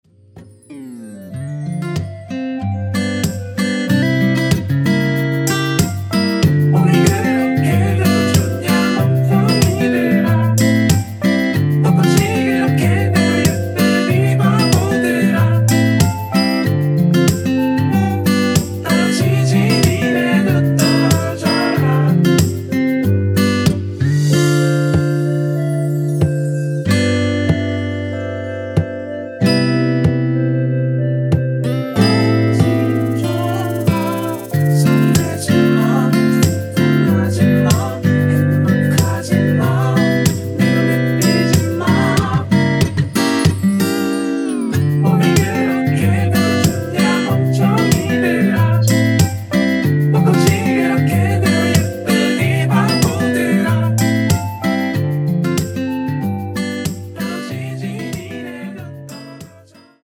원키에서(-1)내린 멜로디와 코러스 포함된 MR 입니다.(미리듣기 확인)
앞부분30초, 뒷부분30초씩 편집해서 올려 드리고 있습니다.
중간에 음이 끈어지고 다시 나오는 이유는